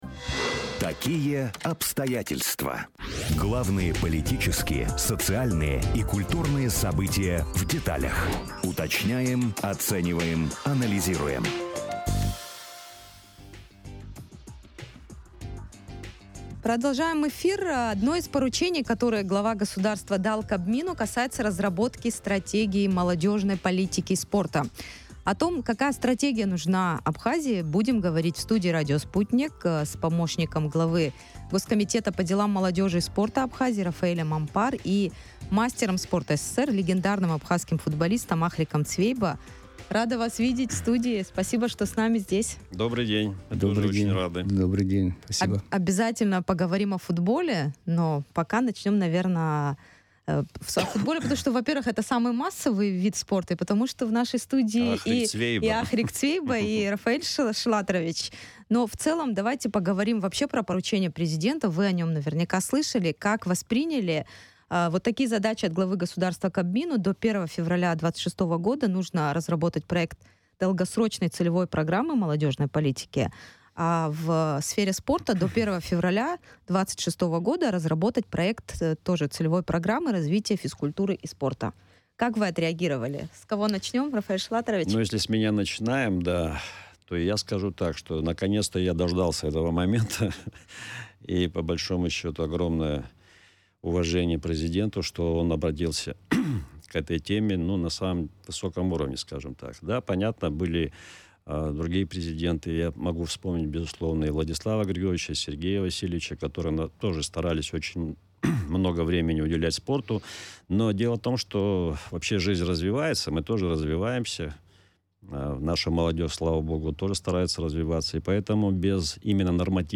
Одно из поручений, которое президент Абхазии дал Кабмину, касается разработки стратегии Молодежной политики и спорта. О том, какая стратегия нужна Абхазии, в эфире радио Sputnik говорили с экспертами.